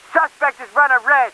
1 channel
VOC_COPS_35_ENGLISH.wav